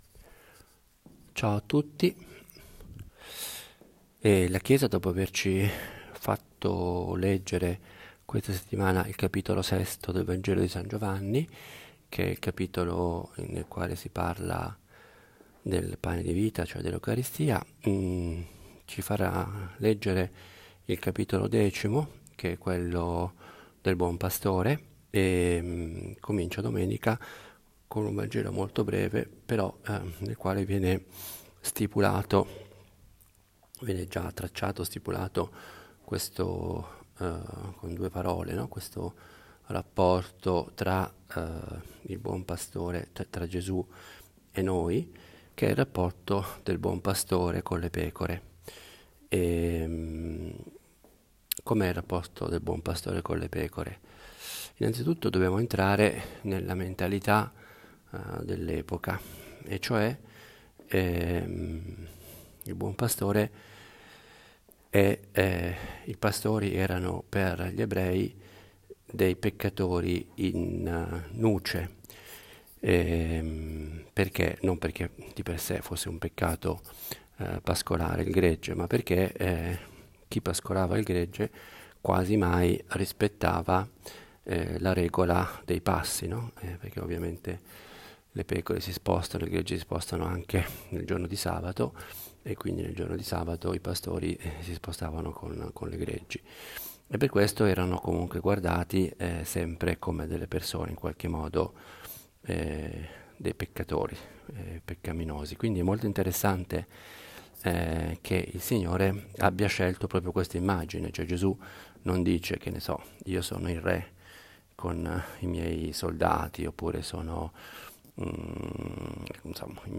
Omelia della IV domenica di Quaresima
Una meditazione nella quale cerco di collegare il vangelo con la vita quotidiana e con la nostra prosa più normale: la frase di un giornale, le parole di una canzone. Vorrei avesse il carattere piano, proprio di una conversazione familiare.